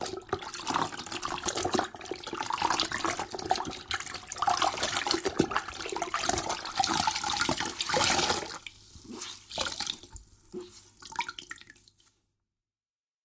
Промываем клизму чистой водой